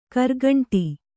♪ kargaṇṭi